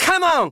Voice clips[edit]
Fox_voice_sample_SSBM.oga